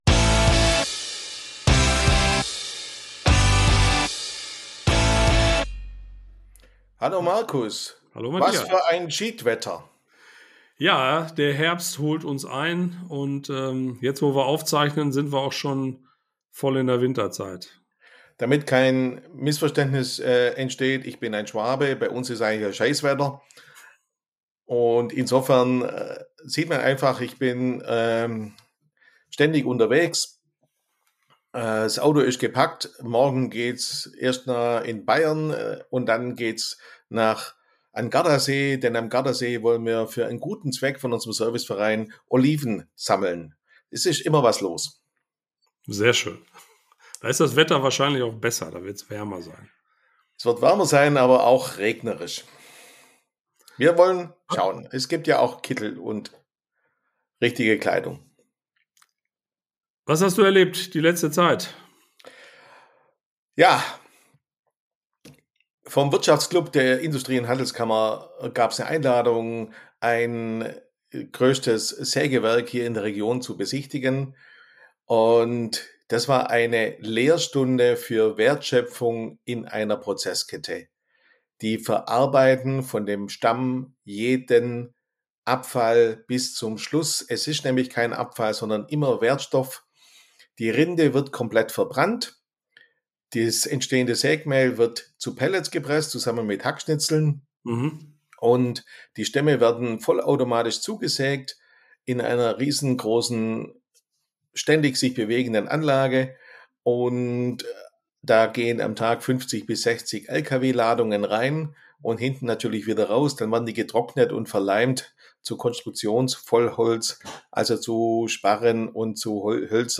Sie erklären, welche Parameter beachtet werden müssen, um optimale Lackierergebnisse zu erzielen. Besonders geht es darum, welche Dinge der Handbeschichter während der Beschichtung kontrollieren sollte. Wie immer ein lockeres Gespräch mit praktischen Tipps, rund um die Pulverbeschichtung.